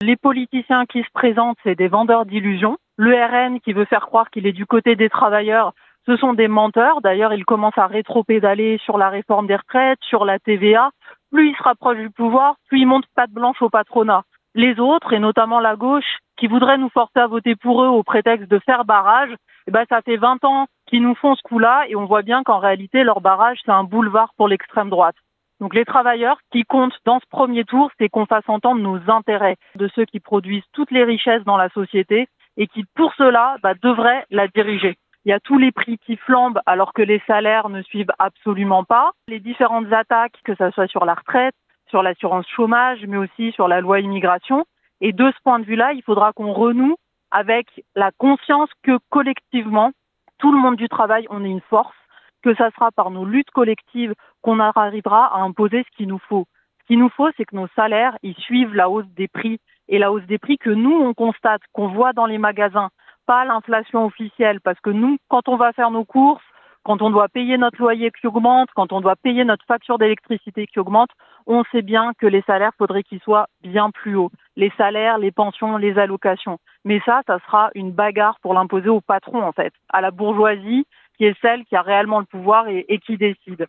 Voici les interviews des 6 candidats de cette 4ème circonscription de Savoie (par ordre du tirage officiel de la Préfecture) :